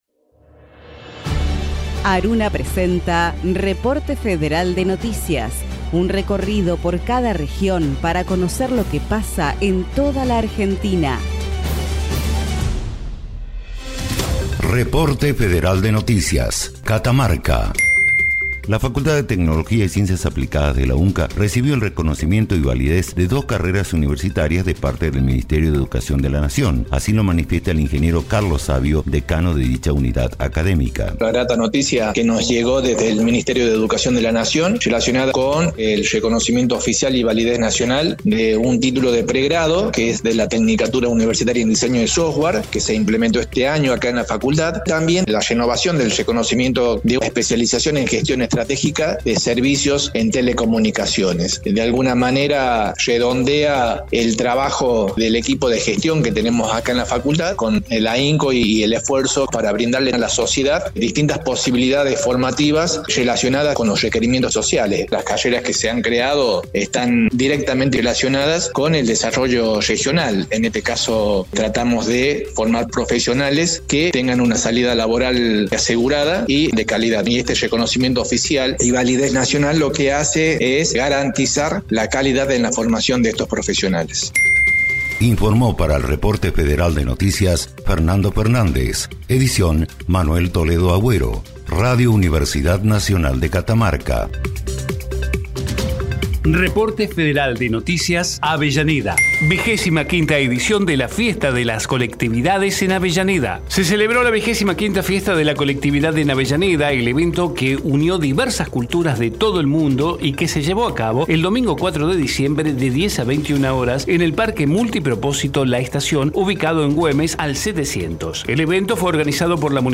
Reporte Federal Texto de la nota: Radio UNDAV - Reporte Federal de noticias Producción colaborativa de ARUNA Las emisoras de universidades nacionales que integran la Asociación de Radios Universitarias Nacionales Argentinas (ARUNA) emiten un informe diario destinado a brindar información federal con la agenda periodística más destacada e importante del día. Un programa que contiene dos noticias por cada radio participante, una noticia institucional de las universidades nacionales y otra local o provincial de interés social, con testimonios de las y los protagonistas locales. Un noticiero federal, inclusivo, plural y dinámico, representativo de todas las voces de nuestro país.